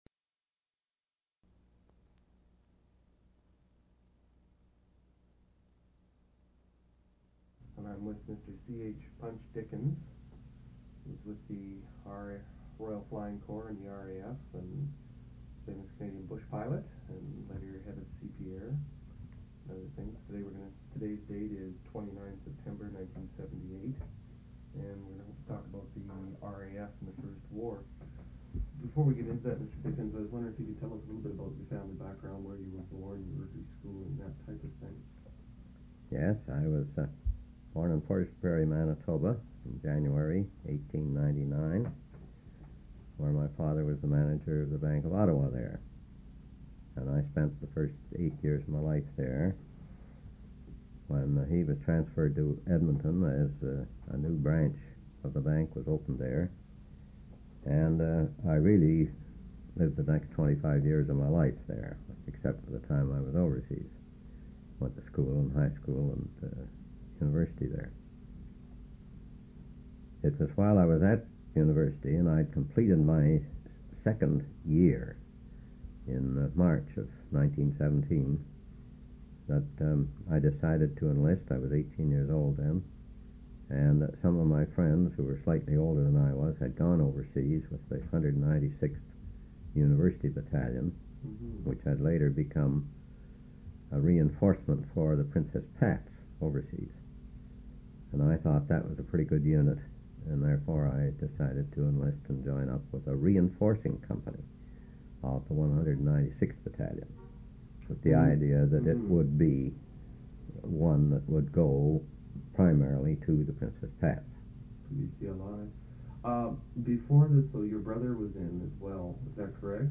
An interview/narrative of Clennell H. Dickins's experiences during World War II. Lieutenant Dickins, O.C., O.B.E., D.F.C. served with the Royal Flying Corps.